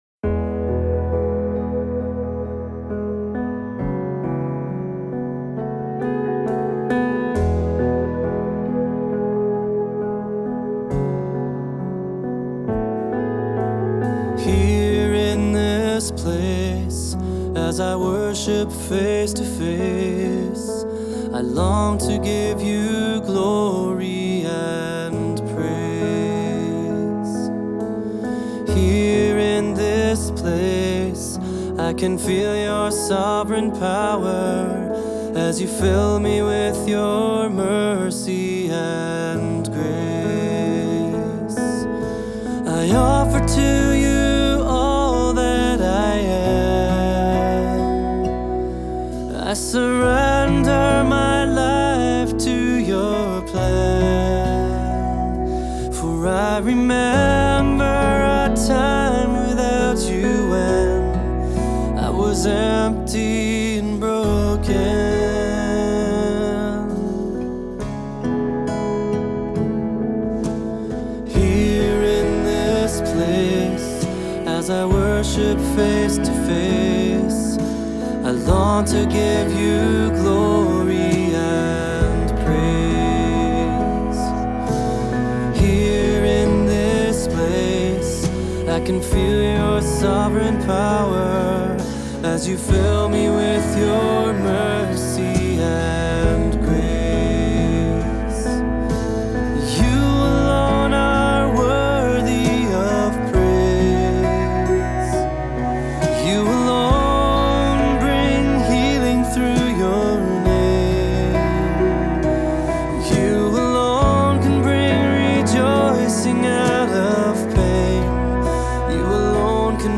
Voicing: Two-part equal; Assembly